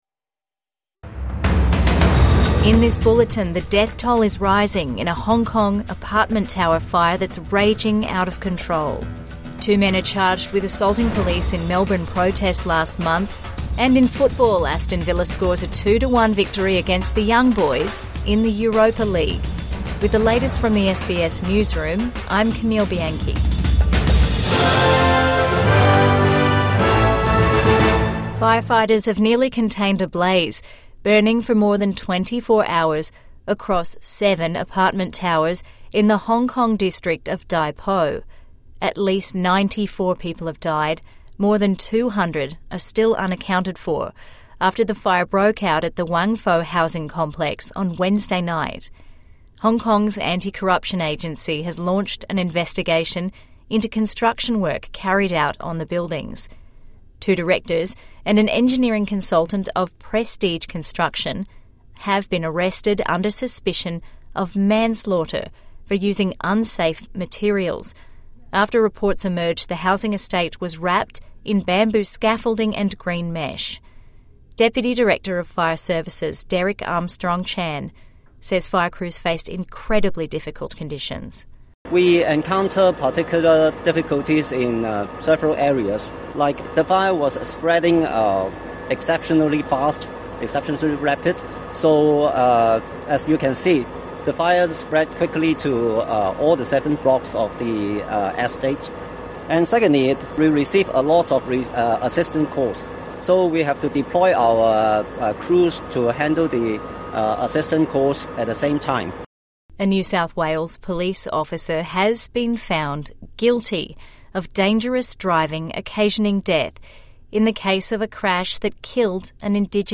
Midday News Bulletin